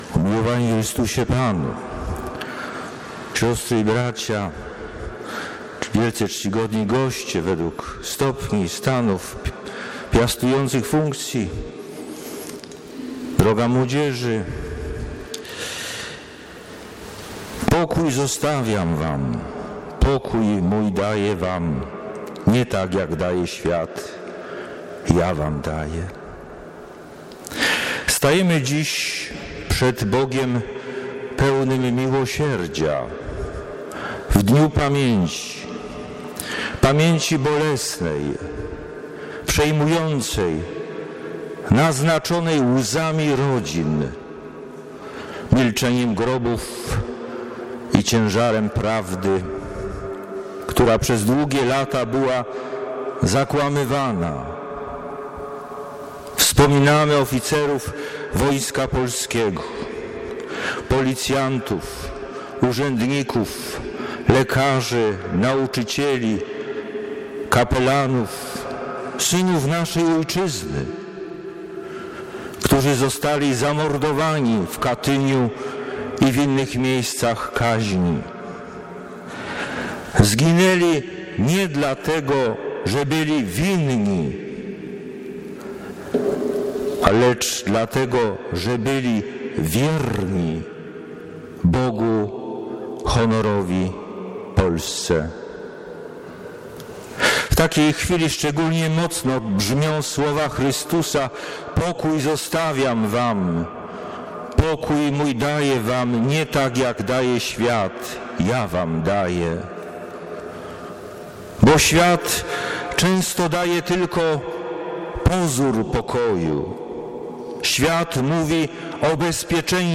W Przemyślu odbyły się uroczystości upamiętniające Ofiary Zbrodni Katyńskiej, Inwalidów Wojennych i rocznicę II masowej deportacji Polaków w głąb ZSRR. Rozpoczęły się uroczystą Mszą św. odprawioną w intencji Ofiar w kościele o.o. Karmelitów Bosych.